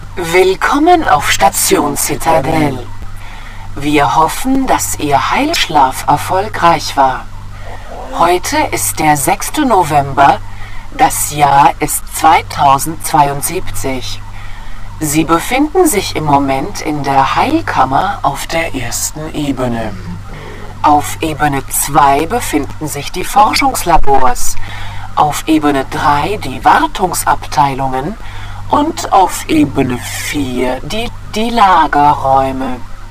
Da Shodan bereits im "Begrüßungs-File" schon etwas verfremdet klingt (wird später noch viel schlimmer bzw. gruseliger), hab ich auch eine Hörprobe der allgemeinen Systemstimme dazugepackt.